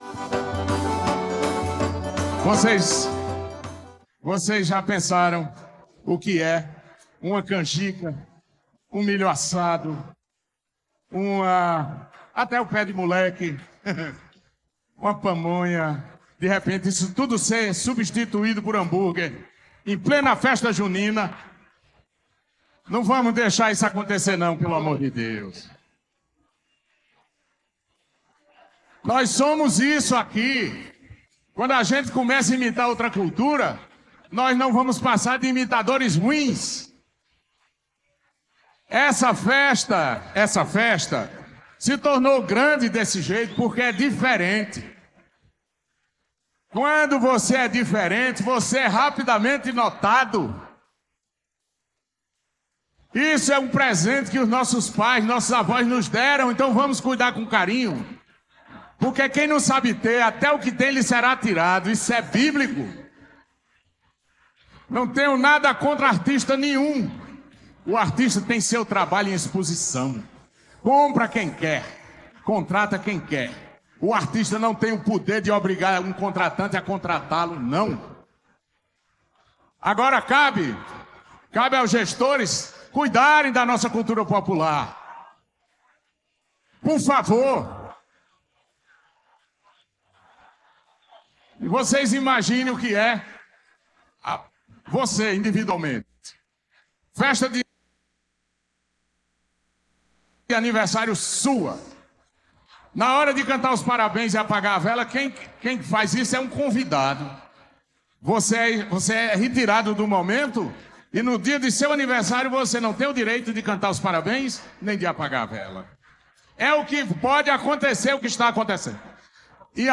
Esse foi o pedido feito pelo cantor de forró Santanna durante sua apresentação no palco do São João de Bananeiras, na noite desta sexta-feira (16).